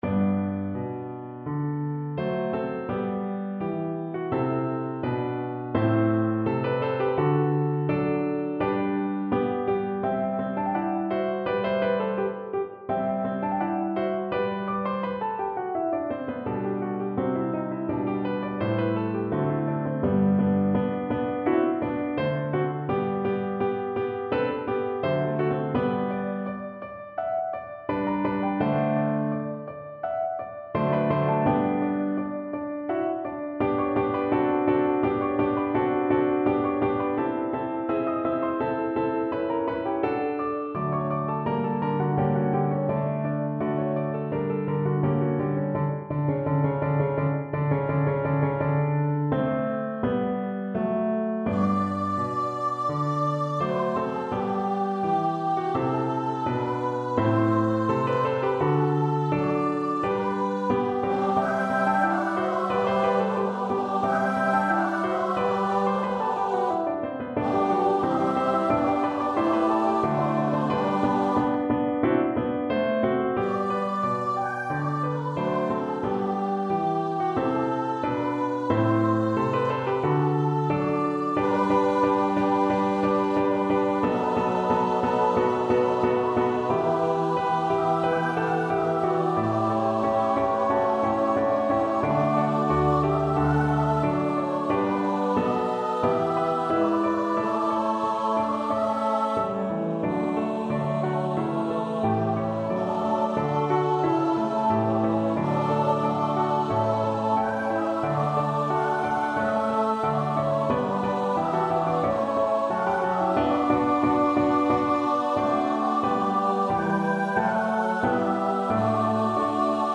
Choir  (View more Intermediate Choir Music)
Classical (View more Classical Choir Music)